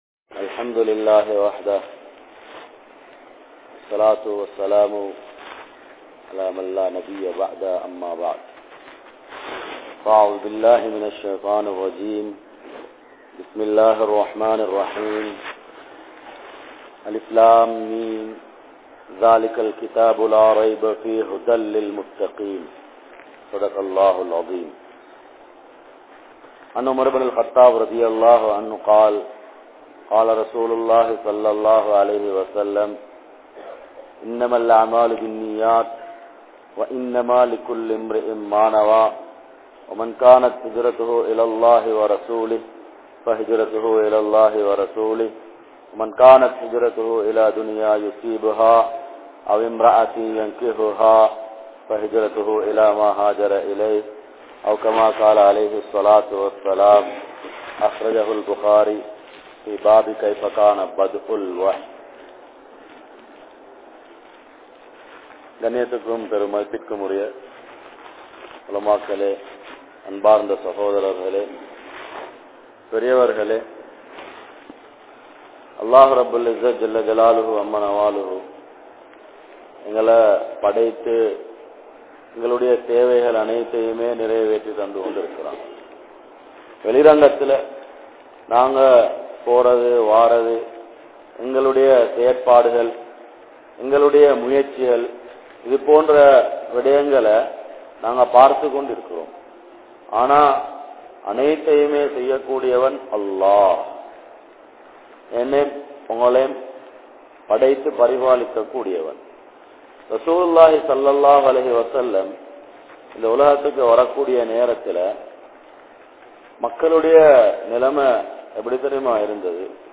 Imaanai Paathuhaappoam (ஈமானை பாதுகாப்போம்) | Audio Bayans | All Ceylon Muslim Youth Community | Addalaichenai
Puttalam, New Jumua Masjidh